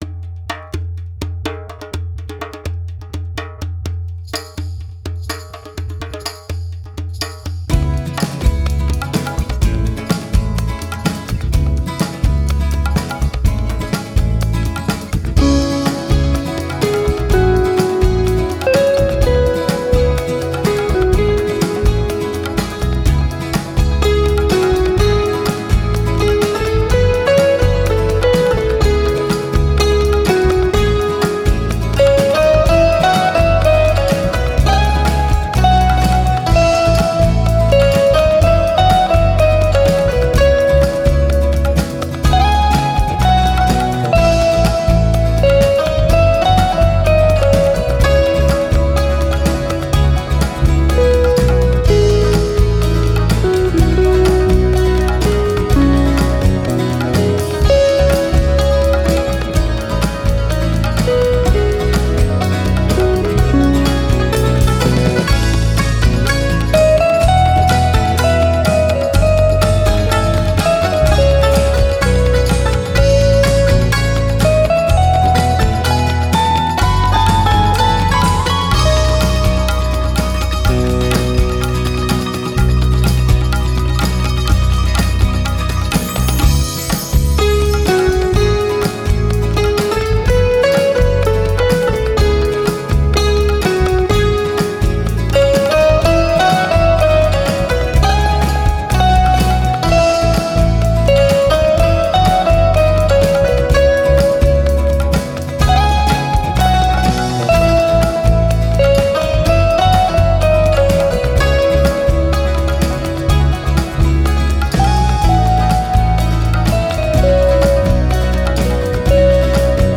Multi-Instrumentalist